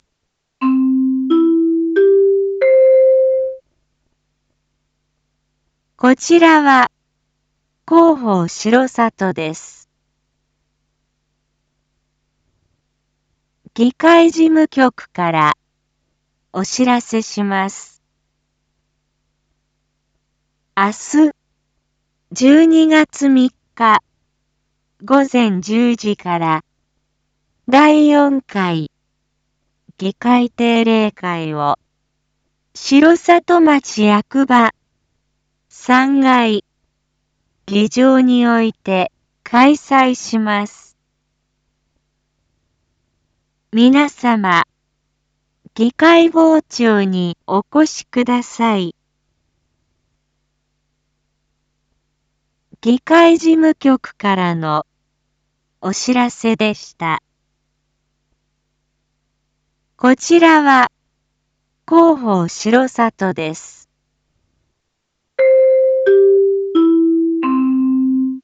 一般放送情報
Back Home 一般放送情報 音声放送 再生 一般放送情報 登録日時：2024-12-02 19:01:11 タイトル：第４回議会定例会① インフォメーション：こちらは広報しろさとです。